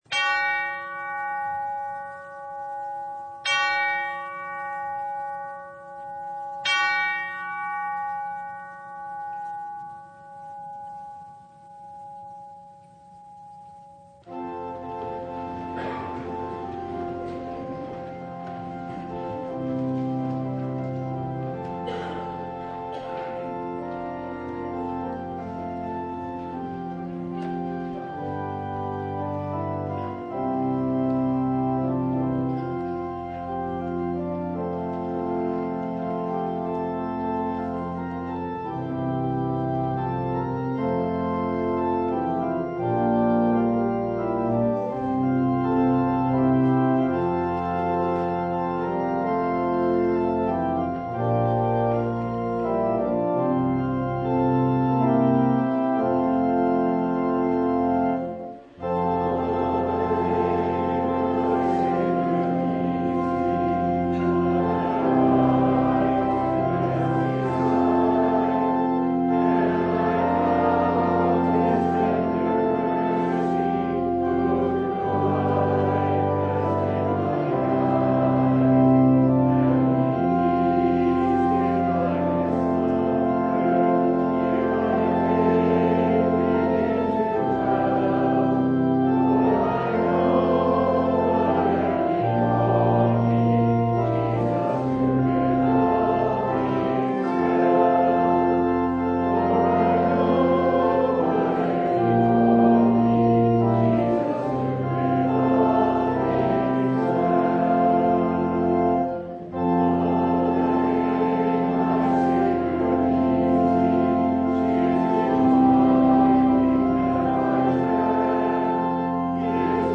Download Files Bulletin Topics: Full Service « Ephphatha!